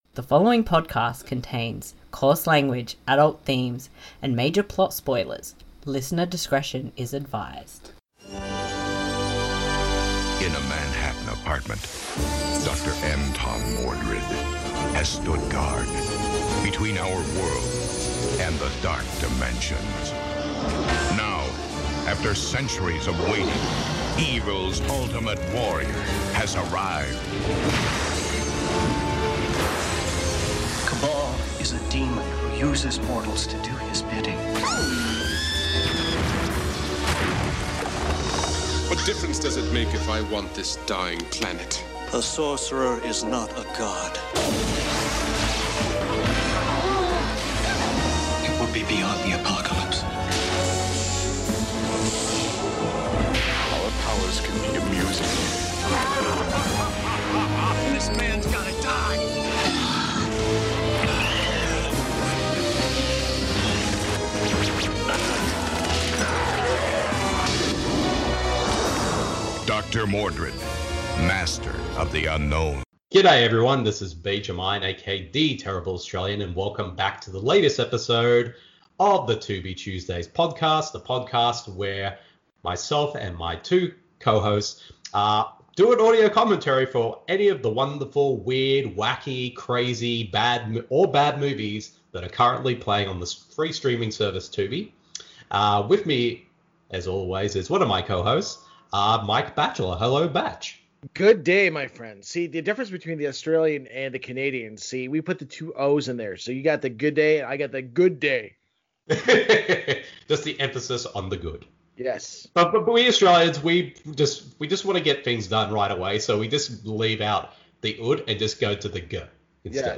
This podcast series is focused on discovering and doing commentaries for films found on the free streaming service Tubi.
Because we have watched the films on Tubi, as it is a free service there are ads, however we will give a warning when it comes up, so you can still listen along.
it isn’t Puppetmaster * Did you know Doctor Mordrid was suppose to be a Doctor Strange film? Seriously, no joke * We have nothing but love for Jeffrey Combs * Since the film is set in New York, we do the NY accent a lot * What is up with all the weird clothes Jeffrey Combs wears in this film?